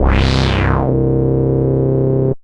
Space Bass.wav